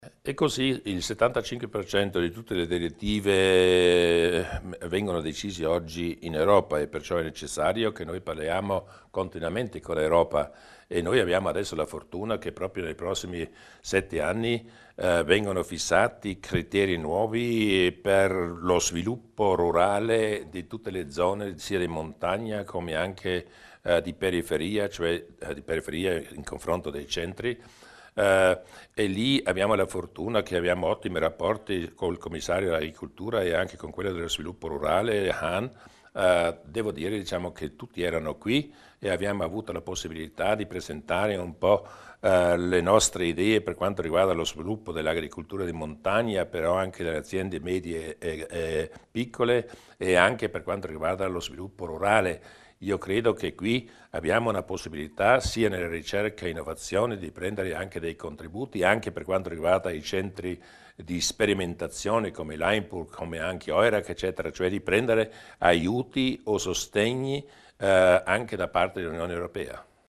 Il Presidente Durnwalder illustra i rapporti con Bruxelles
Mantenere buoni rapporti con Bruxelles, visto che l'Unione Europea è il partner chiave per i programmi di sviluppo del territorio e per la realizzazione del tunnel di base del Brennero, e proseguire nella collaborazione transfrontaliera nell'ambito dell'Euregio Tirolo-Alto Adige-Trentino. Questi alcuni degli obiettivi tracciati dal presidente della Giunta provinciale Luis Durnwalder durante il tradizionale incontro estivo con i media a Falzes.